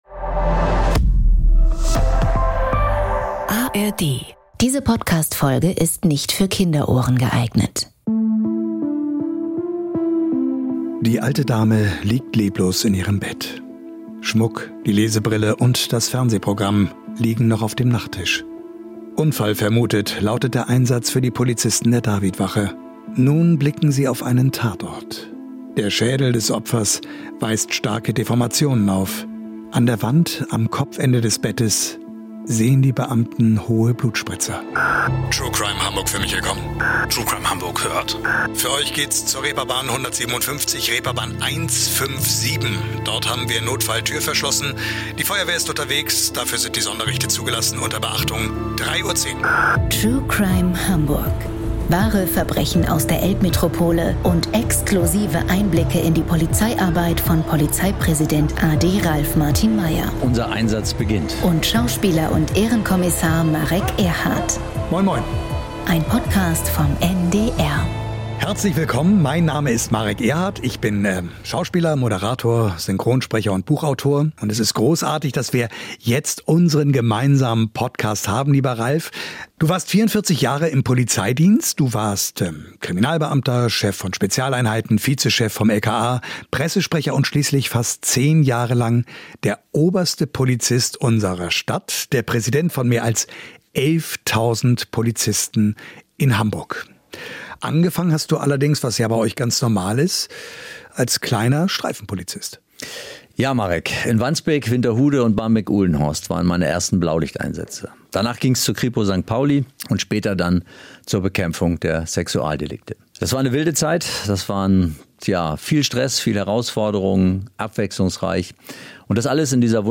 Im Gespräch mit Moderator und Ehrenkommissar Marek Erhardt erzählt Hamburgs Polizeipräsident a.D. von seinen Anfängen, beschreibt die Arbeitsweise der Spurensicherung und erläutert, wie mikroskopisch kleine Farbabriebe zum Täter führen.